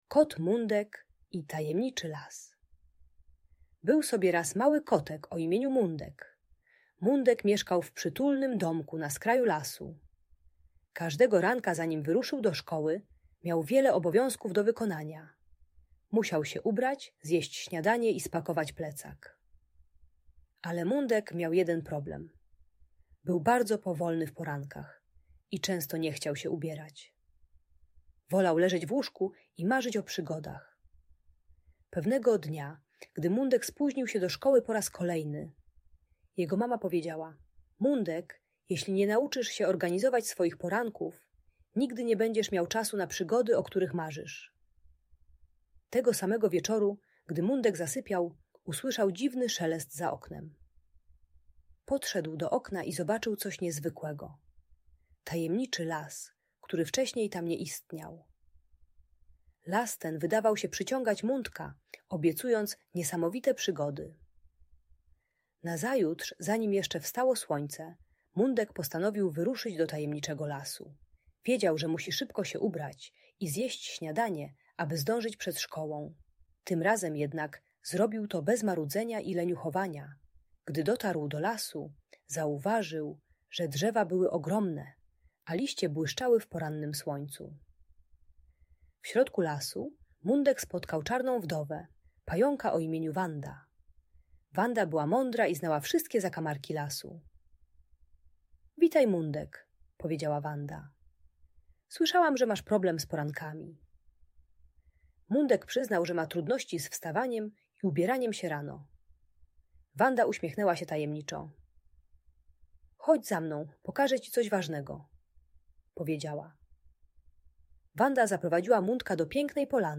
Audiobajka uczy techniki planowania poranków - przygotowania ubrań wieczorem i ustalenia porannej rutyny.